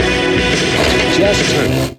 120BPMRAD6-L.wav